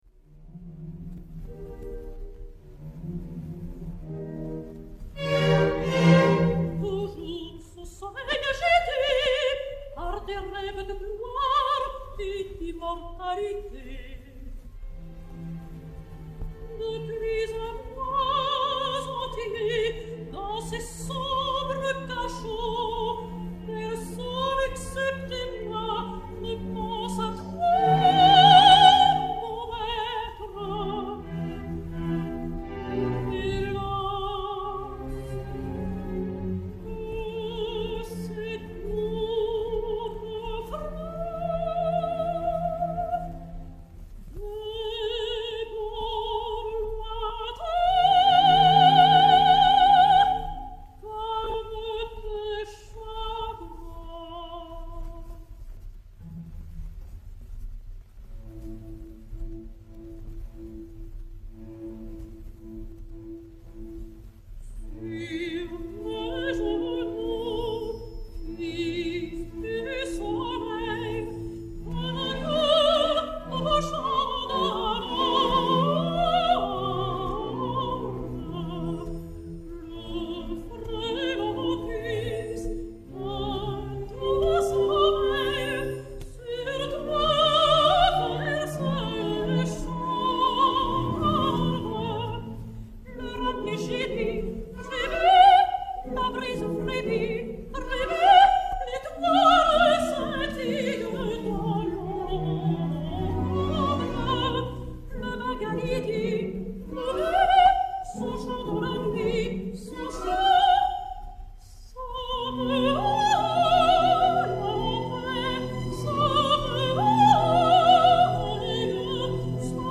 soprano français